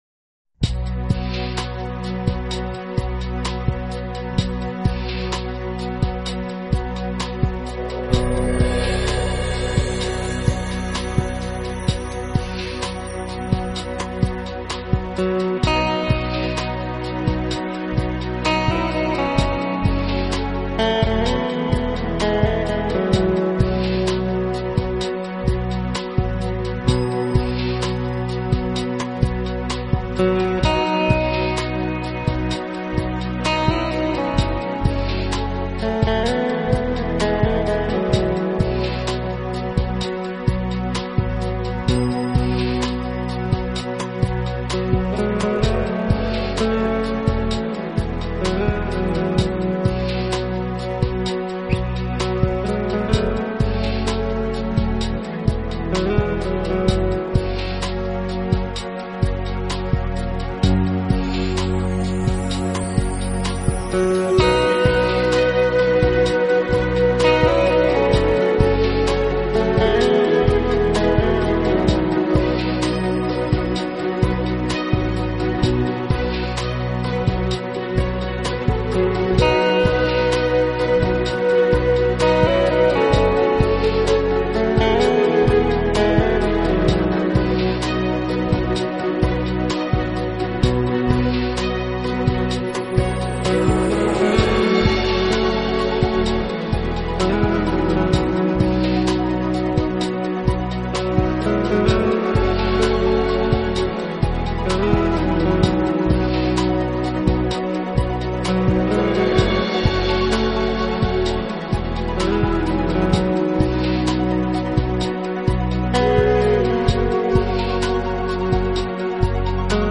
音乐风格：New Age